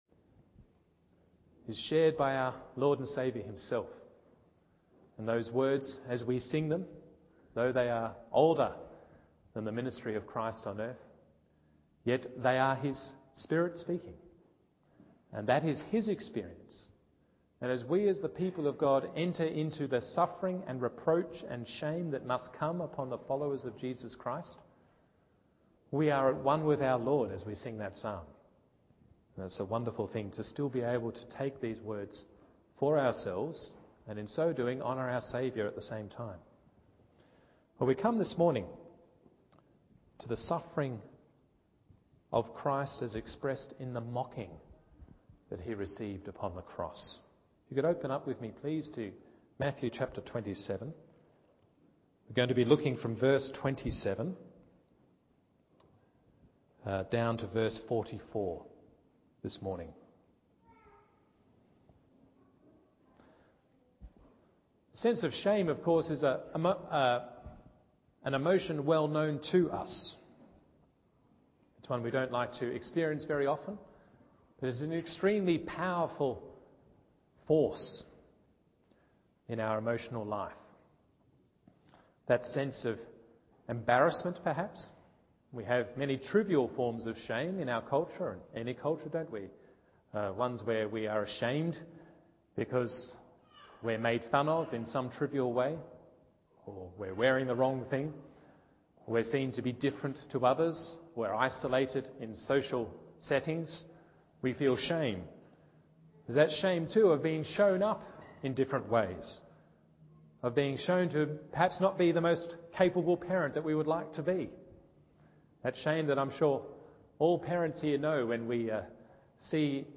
Morning Service Matthew 27:6-44 1. Mockery of the Messiah 2. His shame is His Glory 3. Our Glory must become Shame…